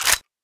Index of /server/sound/weapons/svt40
g3sg1_slide_b.wav